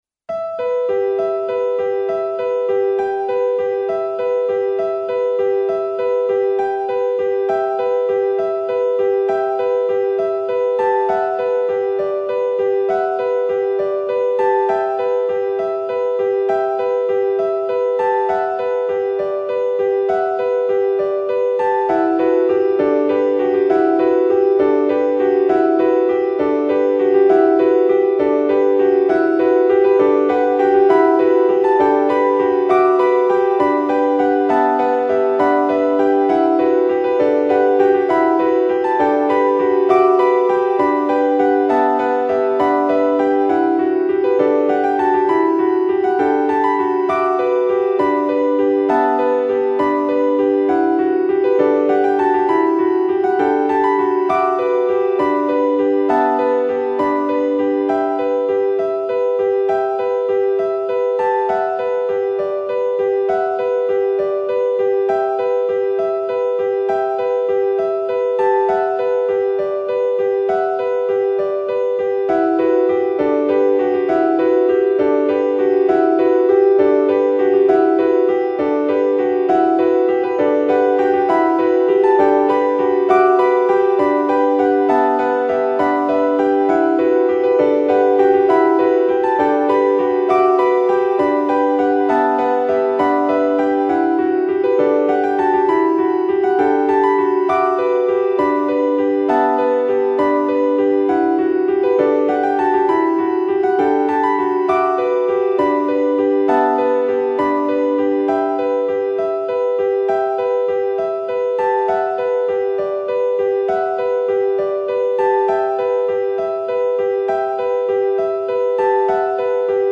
Solo Piano in Em